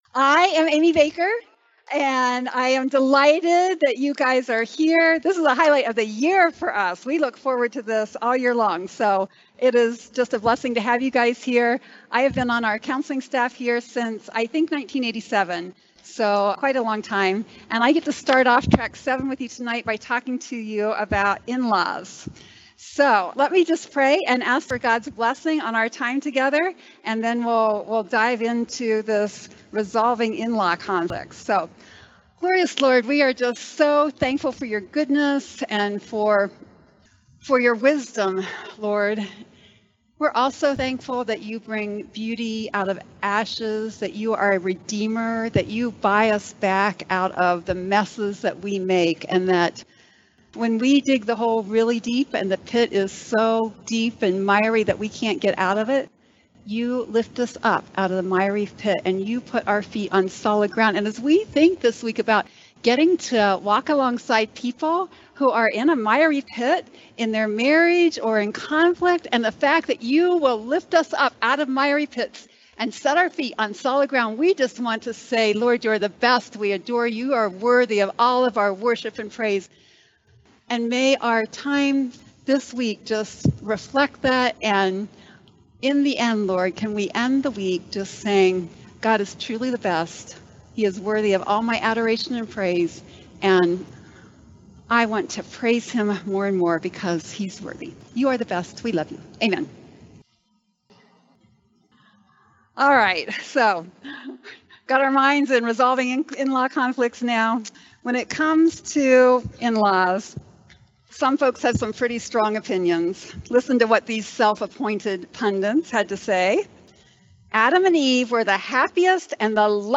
This is a session from the Biblical Counseling Training Conference hosted by Faith Church in Lafayette, Indiana. This session addresses the struggles that children who have been sexually or physically abused carry into adulthood.